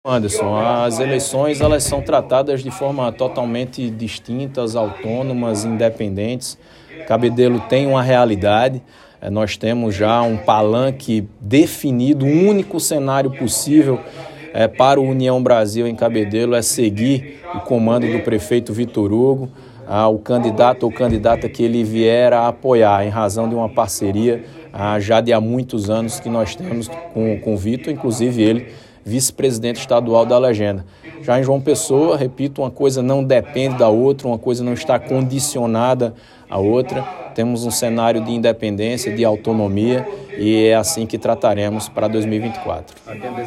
Abaixo a fala do deputado estadual George Morais.